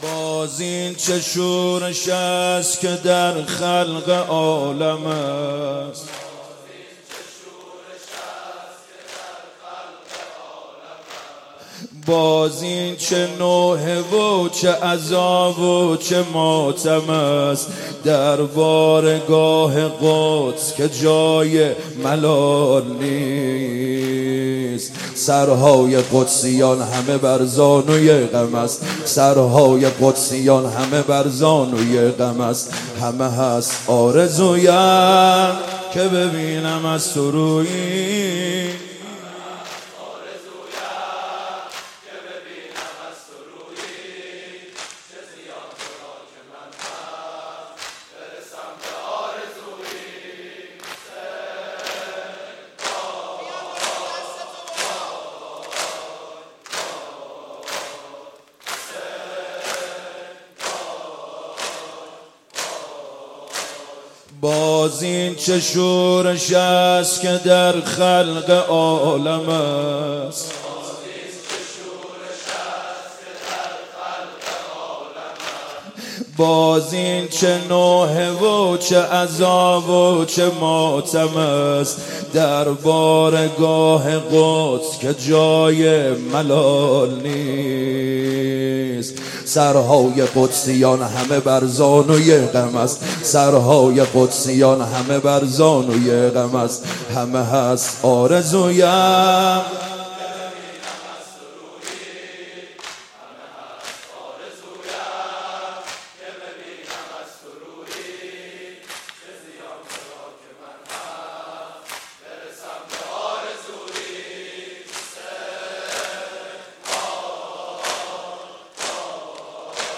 شب دوم محرم97 هیات کربلا رفسنجان